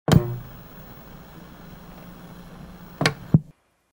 Звук включения и выключения старого телевизора